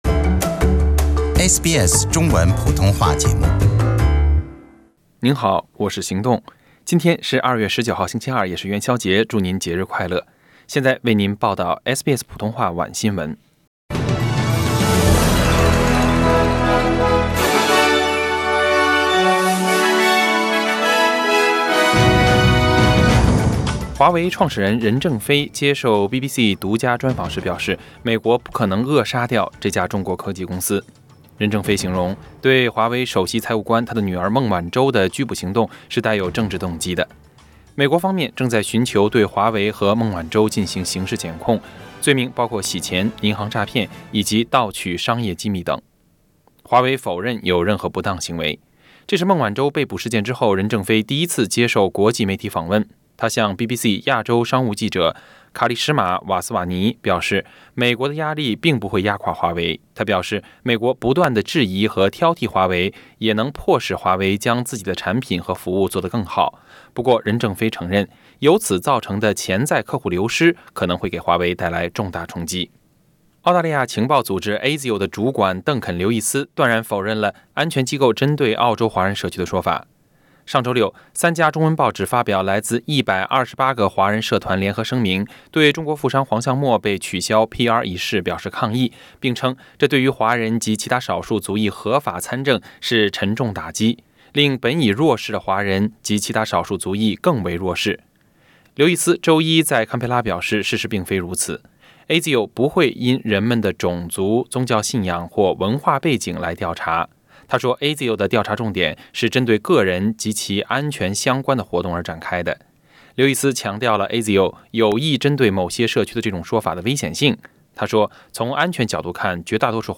SBS晚新闻（2月19日）